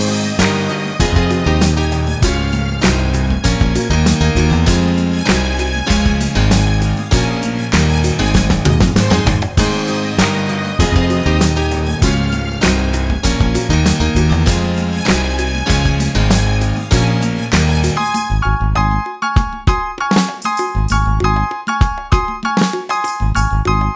No Guitars or Backing Vocals Country (Male) 3:01 Buy £1.50